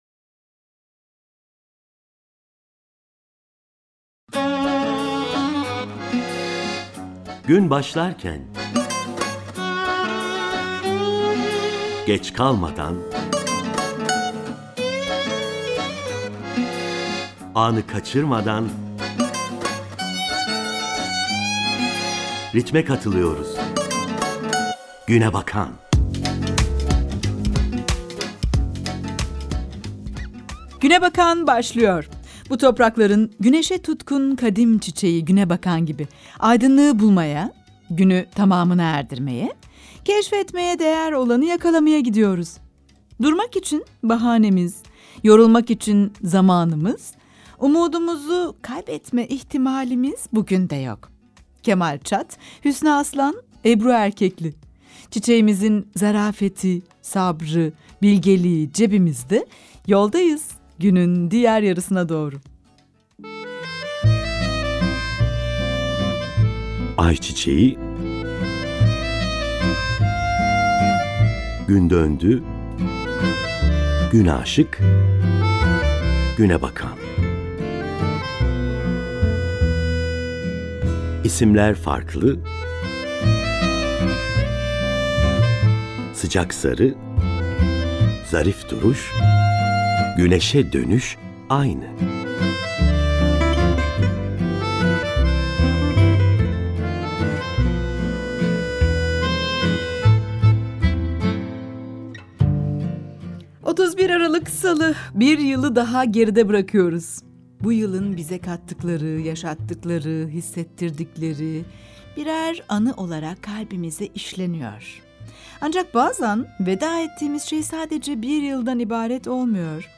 2024 yılını TRT Radyo 1’de kapattık.